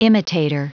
Prononciation du mot imitator en anglais (fichier audio)
Prononciation du mot : imitator